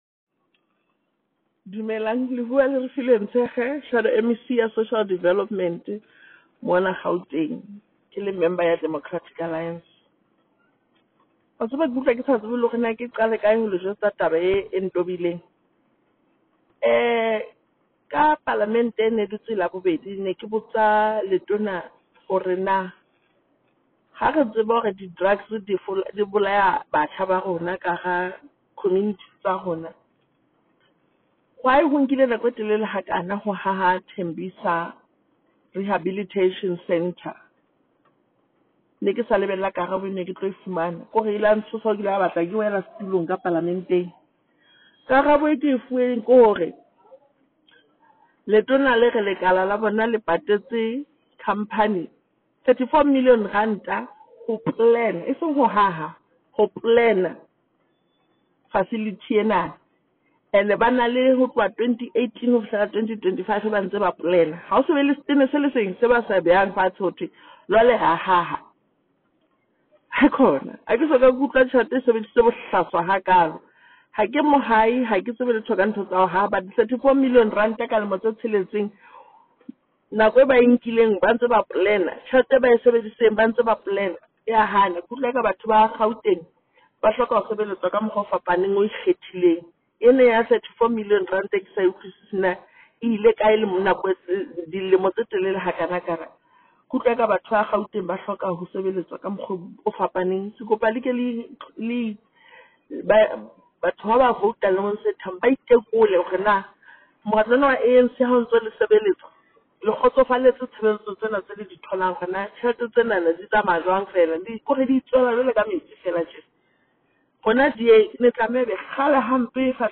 Note to Editors: Attached is a soundbite from DA MPL, Refiloe Nt’sekhe MPL in